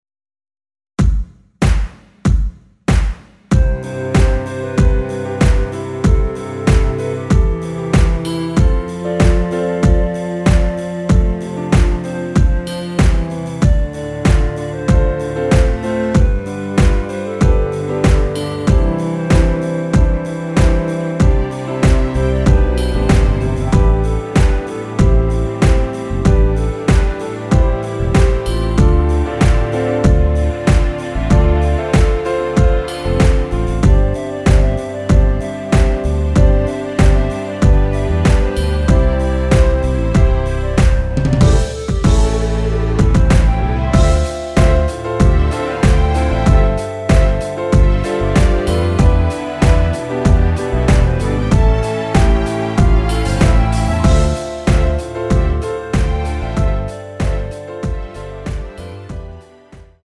엔딩이 페이드 아웃이라 엔딩을 만들어 놓았습니다.
앞부분30초, 뒷부분30초씩 편집해서 올려 드리고 있습니다.
중간에 음이 끈어지고 다시 나오는 이유는